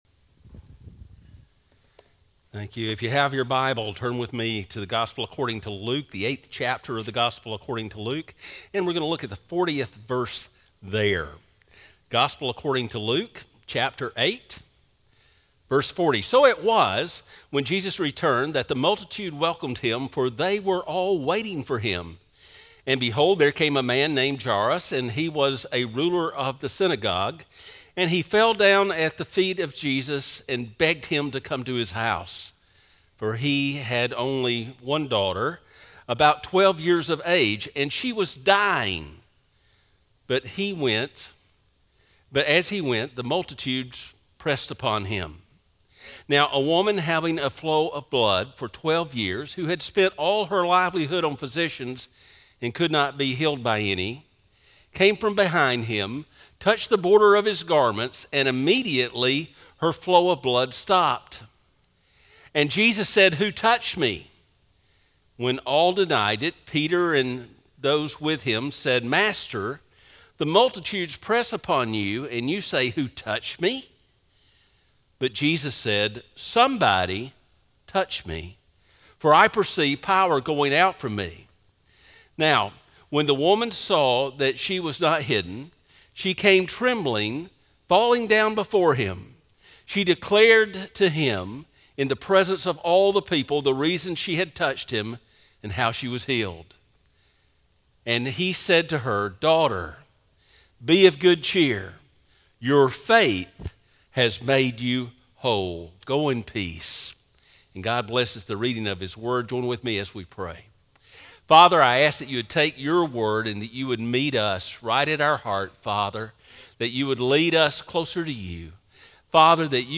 Sermons - Northside Baptist Church